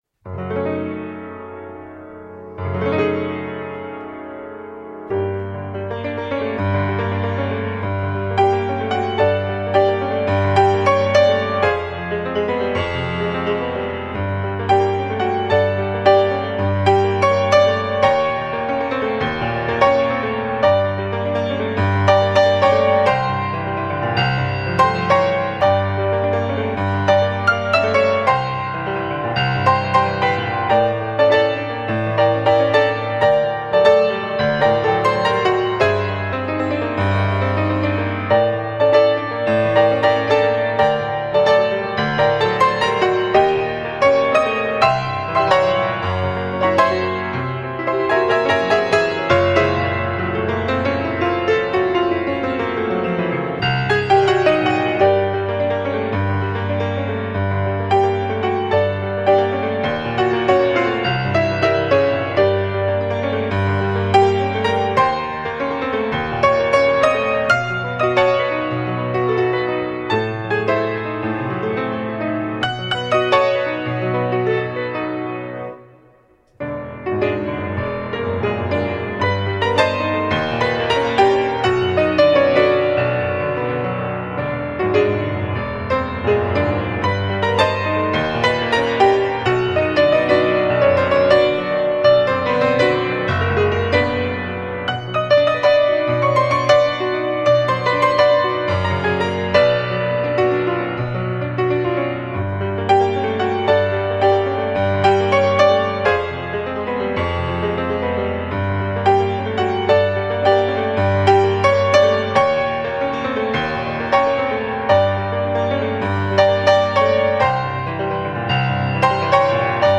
Exquisite original melodic pieces.